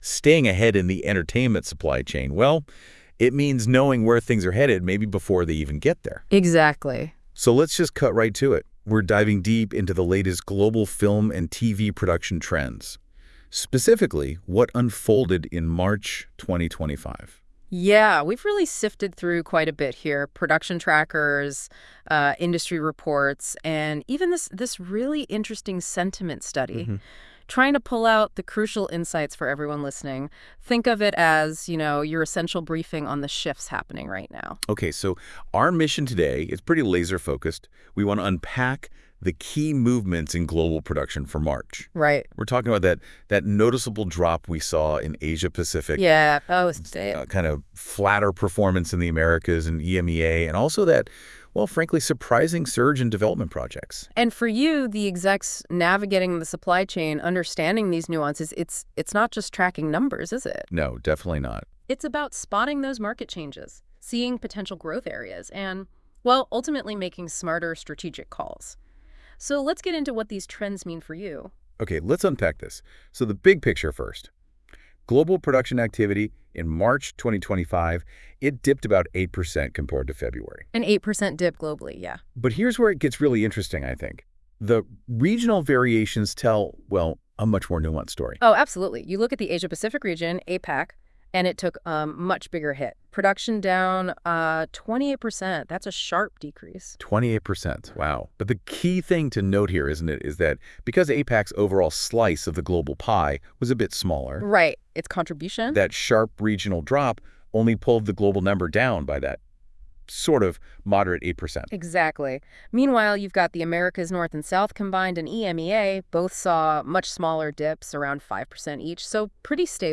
The audio podcast was generated with Deep Dive and reviewed by our team.